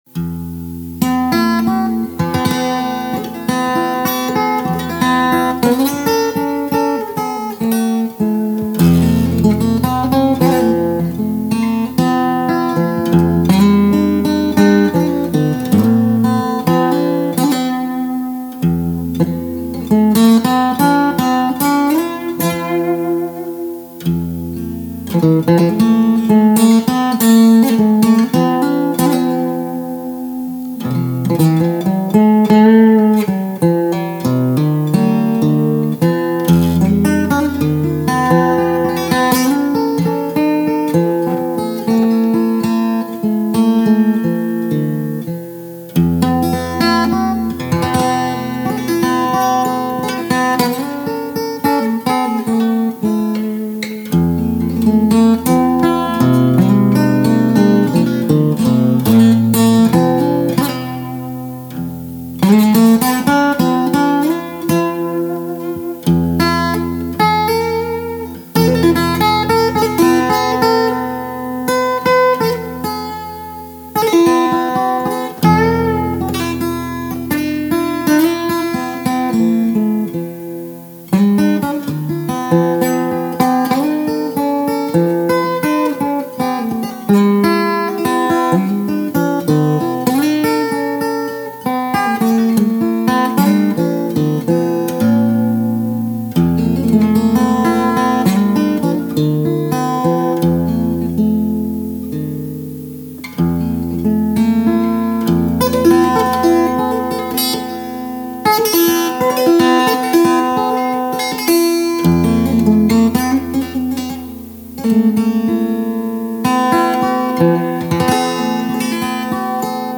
This isn’t a song I can really do justice to vocally, but after discussing arrangements with a friend a few months ago, I thought it would be nice to work on an instrumental arrangement.
This takes it back to a much slower reading, but does use that variation in the tune.
This is in DADGAD: I was trying out a version in standard tuning a few months ago that I quite liked.